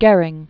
(gĕrĭng, gûr-, gœrĭng), Hermann Wilhelm